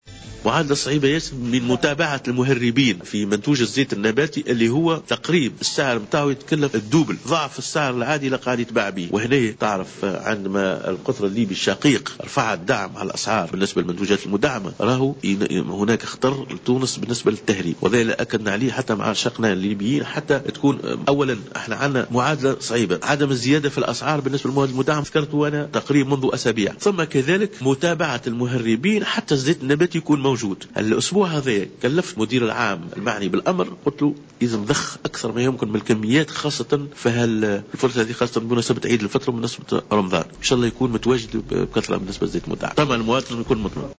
خلال حضوره أشغال المجلس الإداري الوطني لمنظمة الدفاع عن المستهلك في نابل